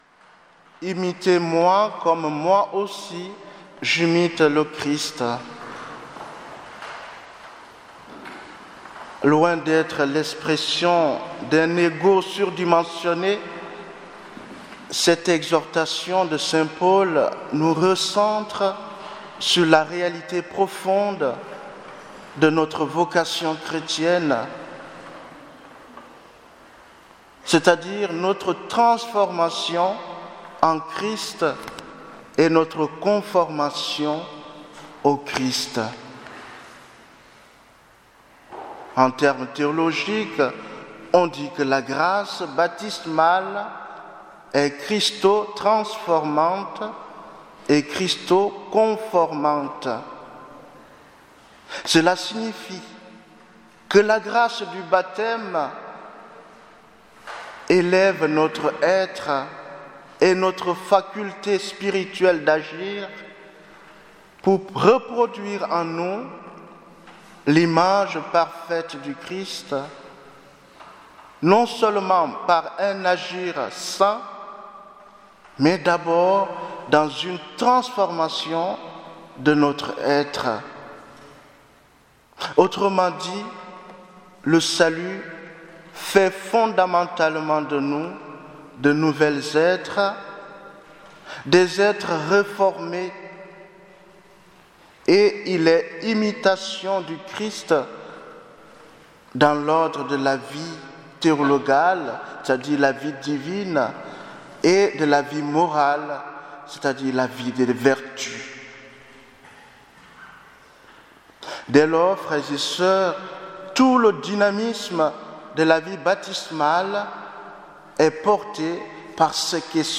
Homélie du dimanche 11 février